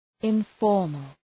Προφορά
{ın’fɔ:rməl}